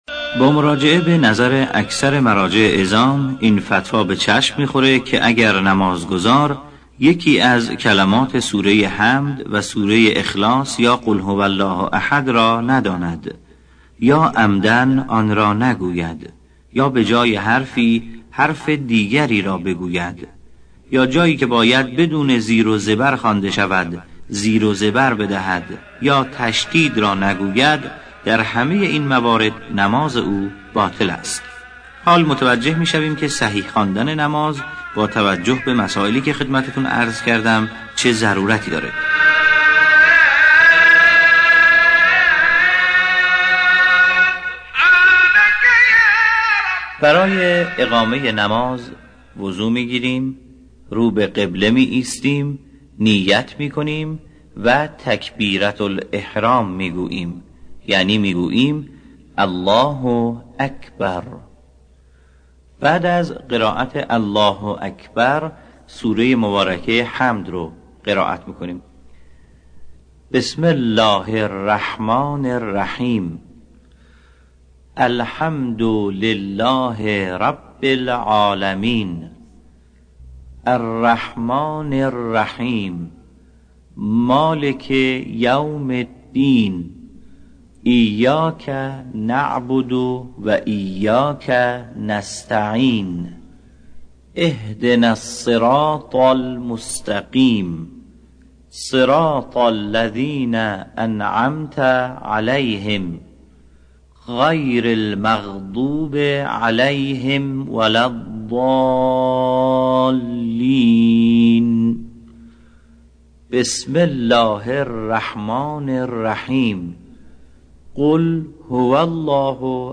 دسته : آموزش زیبا خوانی نماز